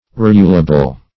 Search Result for " rulable" : The Collaborative International Dictionary of English v.0.48: Rulable \Rul"a*ble\, a. That may be ruled; subject to rule; accordant or conformable to rule.